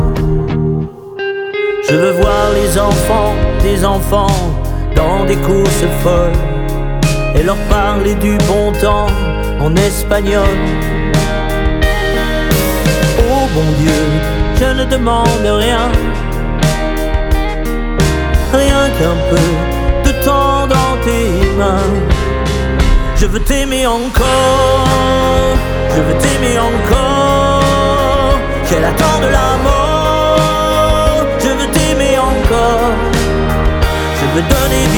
French Pop
Жанр: Поп музыка